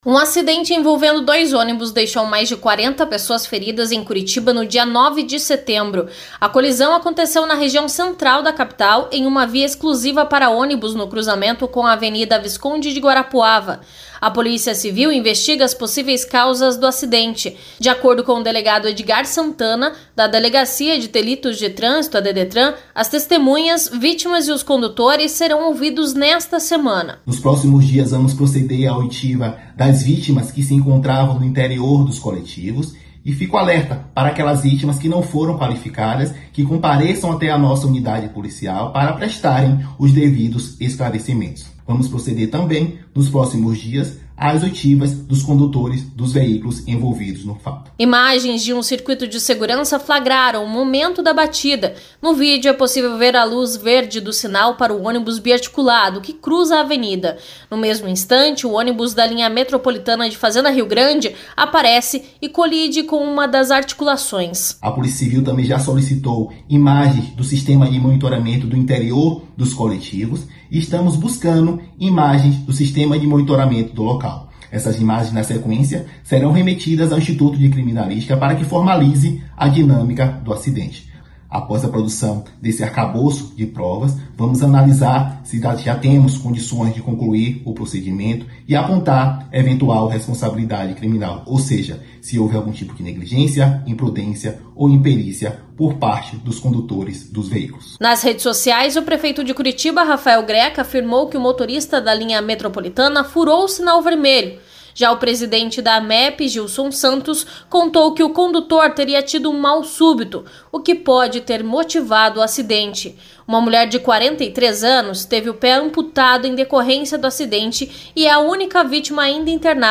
Sonora: PCPR investiga causas do acidente que deixou 40 pessoas feridas após batida de ônibus em Curitiba